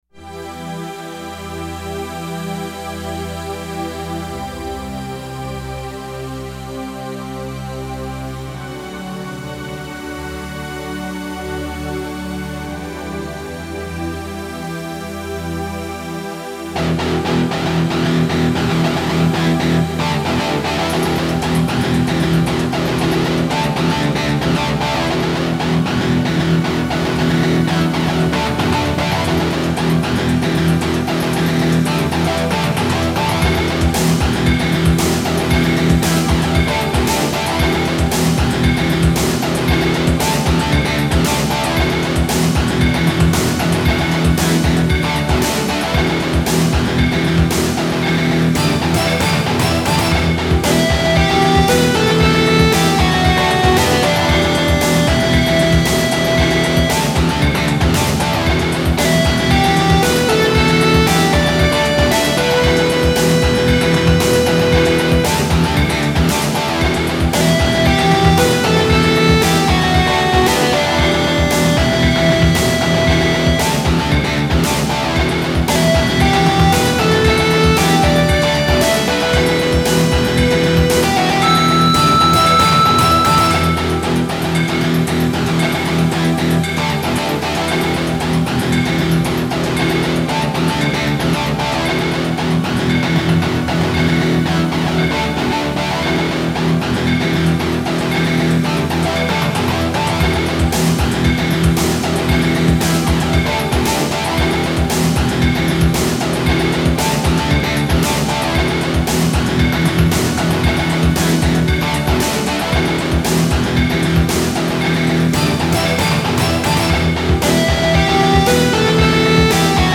cover/remix